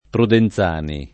Prudenzani [ pruden Z# ni ] → Prodenzani